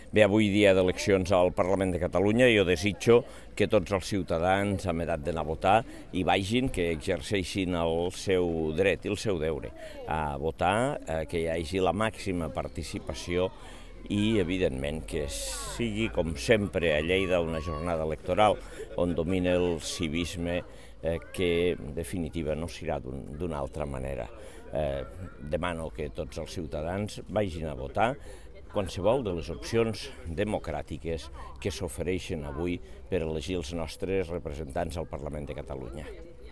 Tall de veu de l'alcalde de Lleida sobre la jornada electoral.
tall-de-veu-de-lalcalde-de-lleida-sobre-la-jornada-electoral